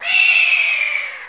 1 channel
hawk2.mp3